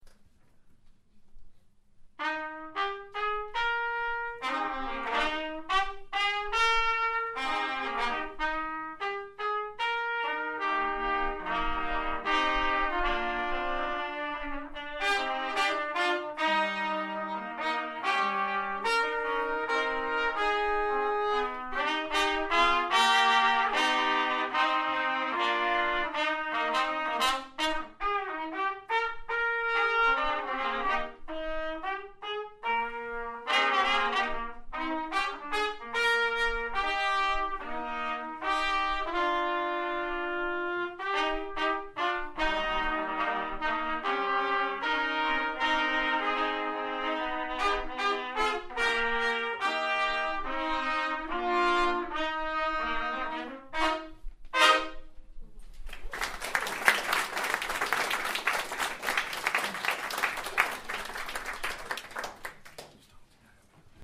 Senior Trumpets